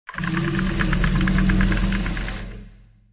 Hybrid Monster Roar Sound Button - Free Download & Play
Games Soundboard1,501 views